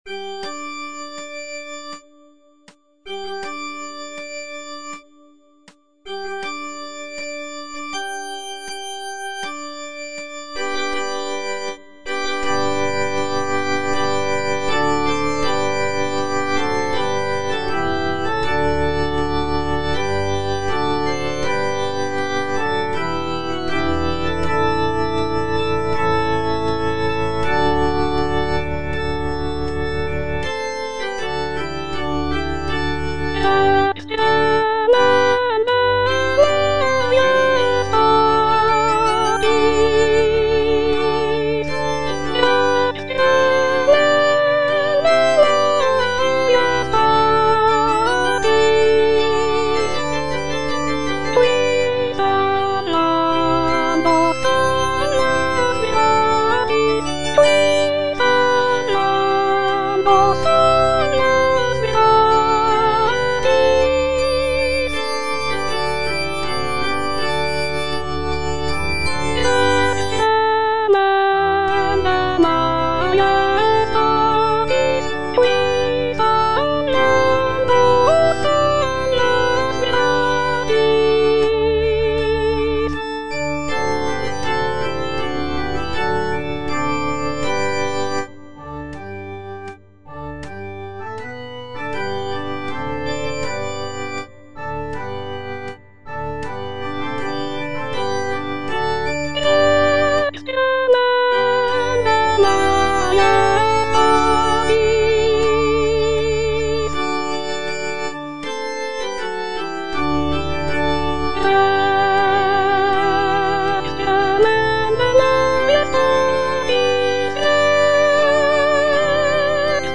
soprano I) (Voice with metronome